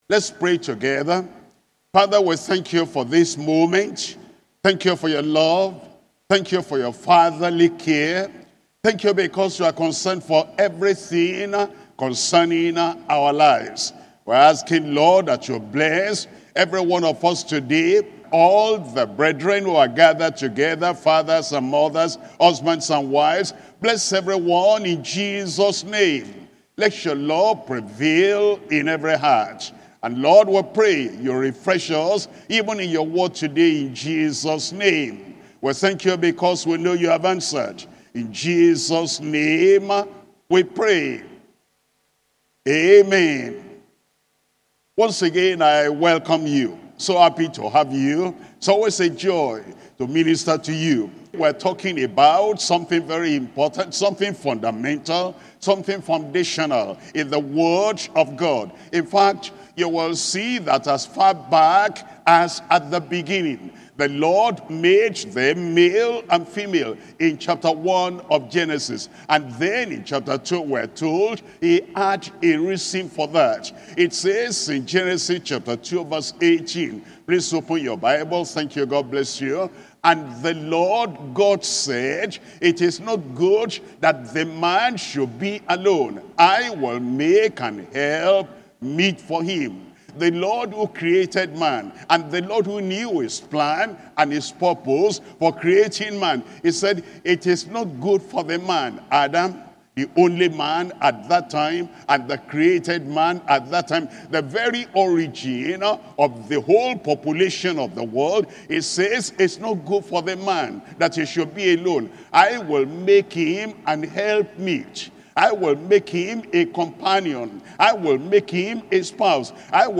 Sermons - Deeper Christian Life Ministry
2025 Global Family and Marriage Conference